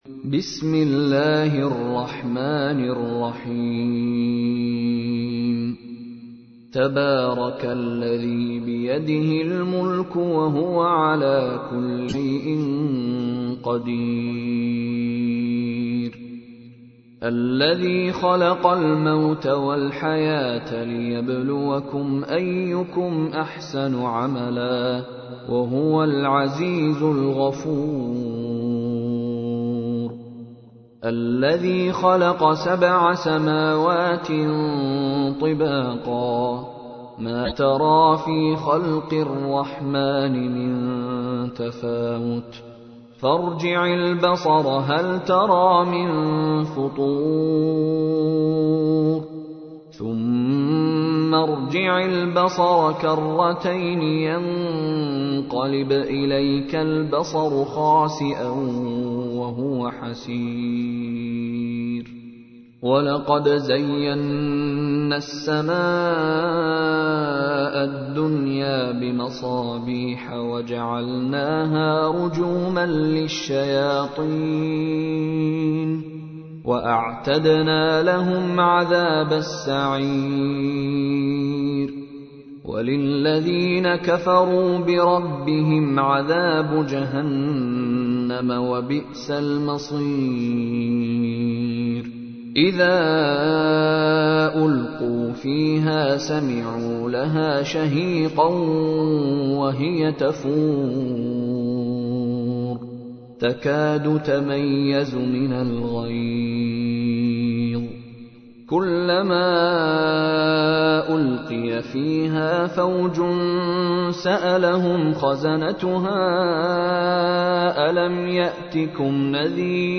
تحميل : 67. سورة الملك / القارئ مشاري راشد العفاسي / القرآن الكريم / موقع يا حسين